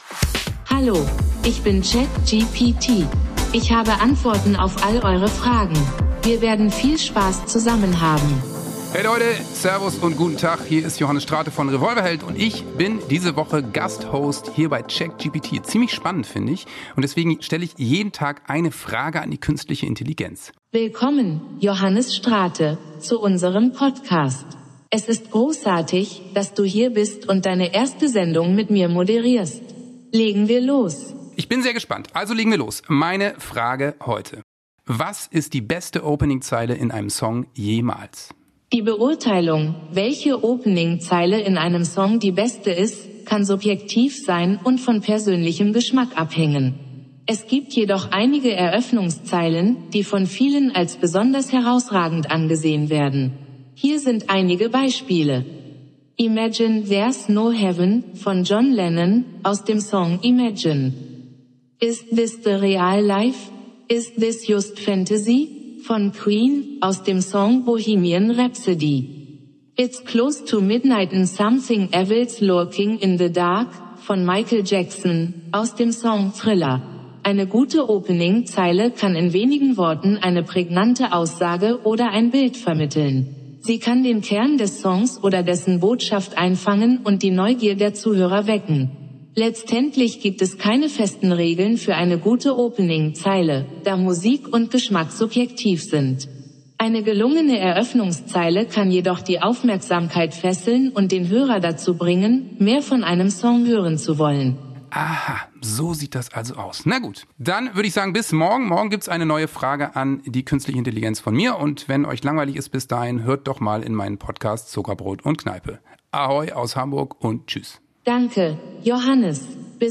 Johannes Strate & KI